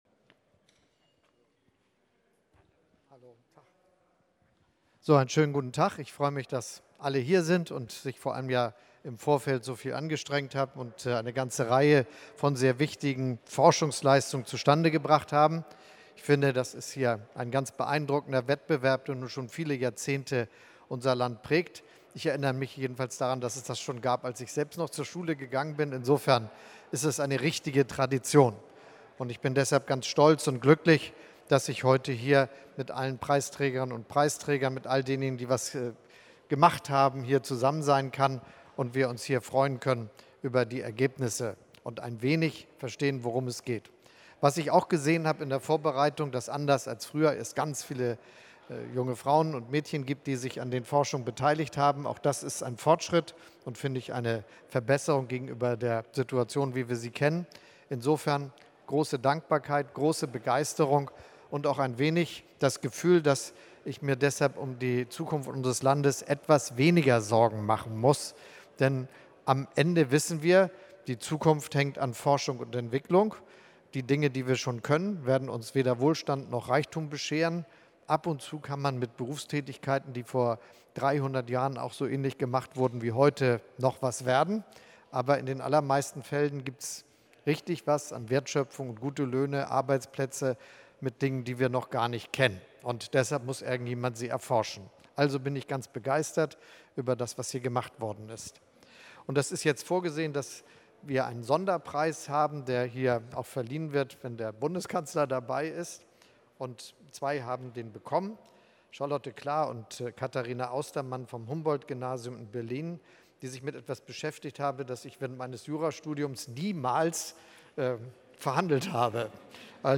Bundeskanzler Olaf Scholz hat am Dienstag die Preisträgerinnen und Preisträger des Bundeswettbewerbs „Jugend forscht“ in Berlin empfangen.